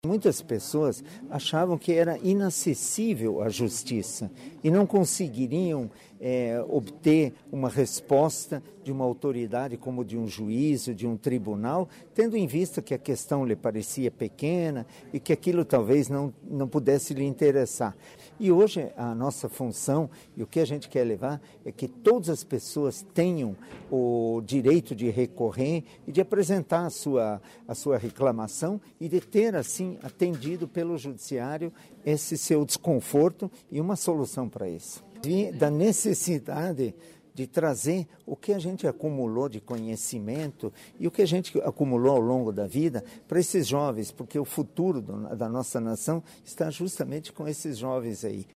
Segundo o desembargador aposentado, Guido Döbeli, ações como o “Geração Atitude” mostram aos jovens que os poderes estão acessíveis a todo cidadão. Confira a entrevista.